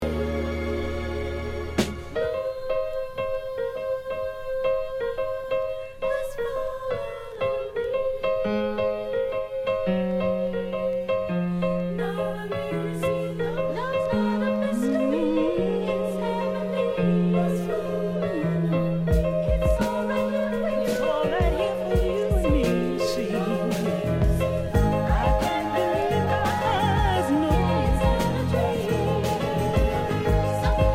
Tag       JAZZ OTHER